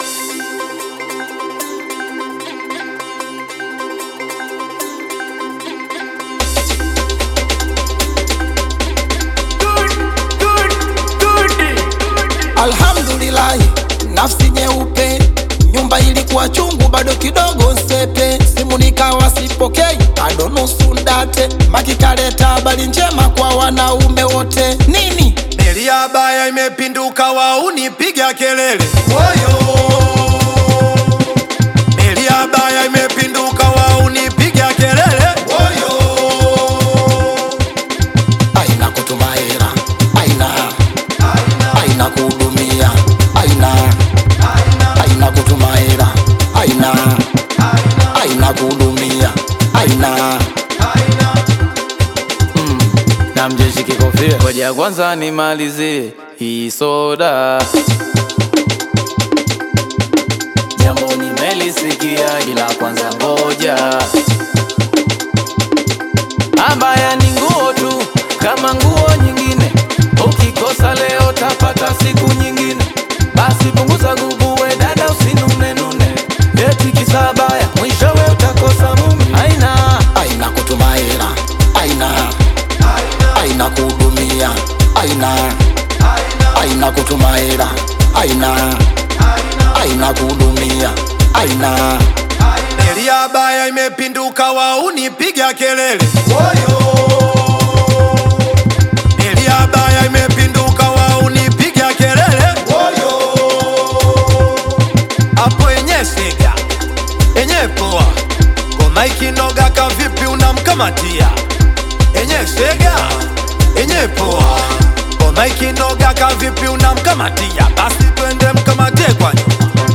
Genre: Singeli